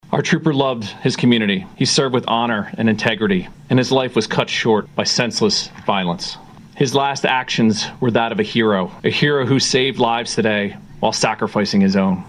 Superintendent of the Delaware State Police Col. William Crotty during a press conference Tuesday night in Wilmington said the State Police family has suffered a devastating loss….
DSP-Superintendent-Col-William-B.-Crotty.mp3